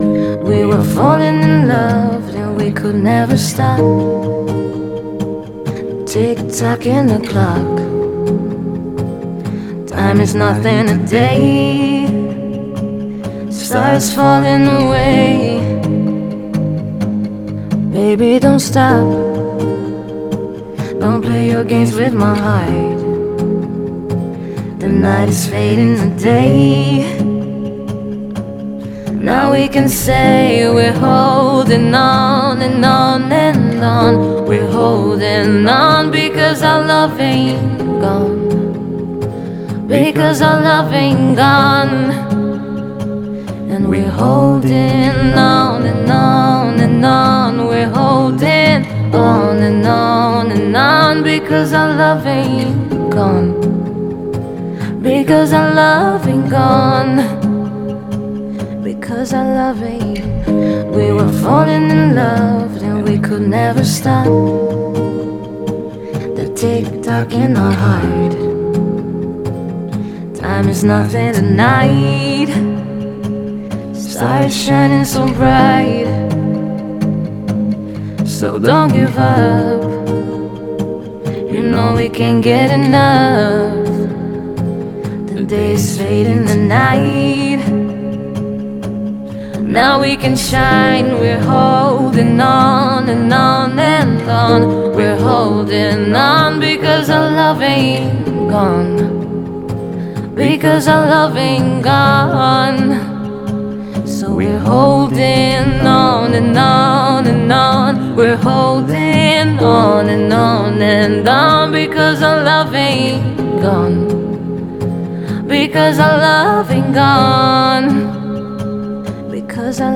это трек в жанре электронная музыка